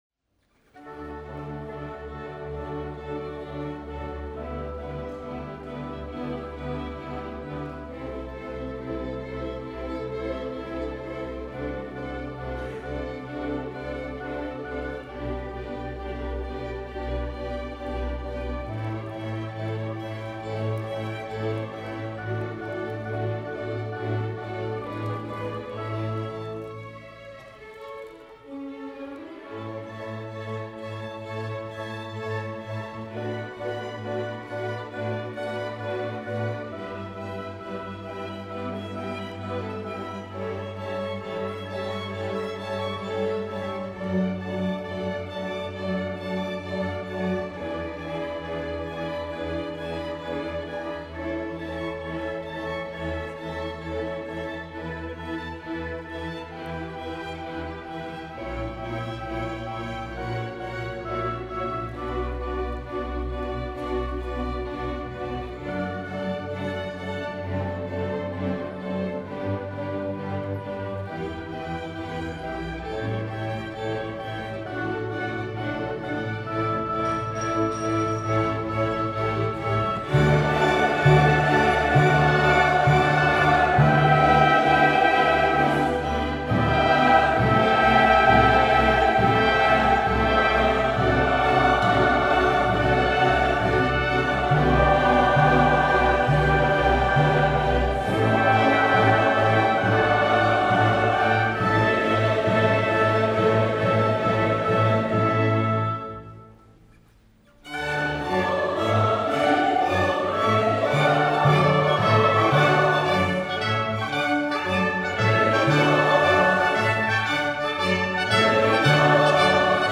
Santa Barbara City College Symphony and Chorus Concert, May 12 & 13, 2012
SBCC Symphony and Concert Choir Concert Recording - May 12 and 13, 2012